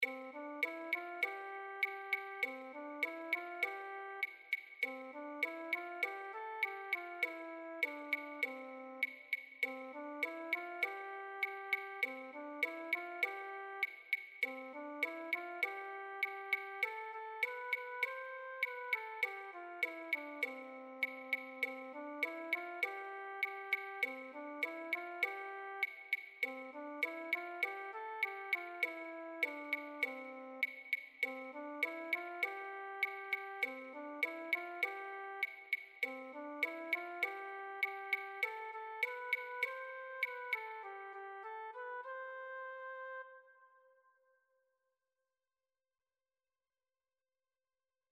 1.PERC.
La-escalerita-f-1.PERCUSION.mp3